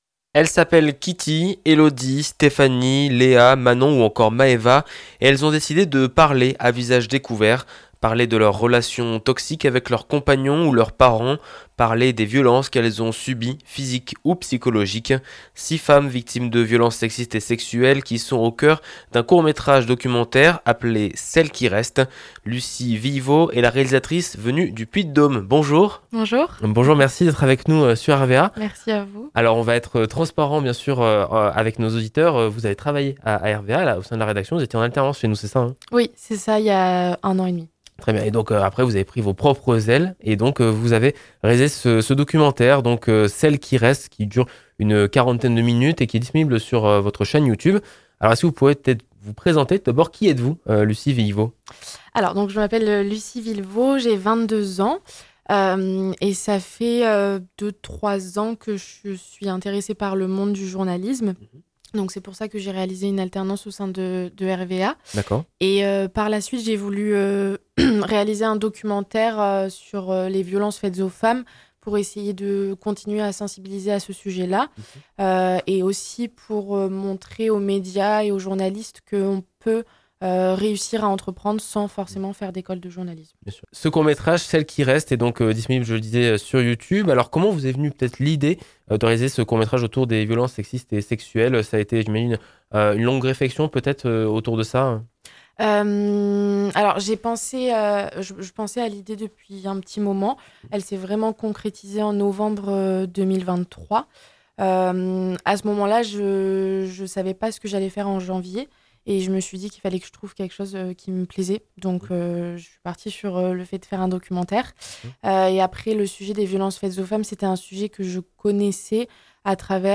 À visage découvert et avec beaucoup de détails, six femmes, de tous âges, victimes de violences physiques ou psychologiques, prennent la parole. Rencontre.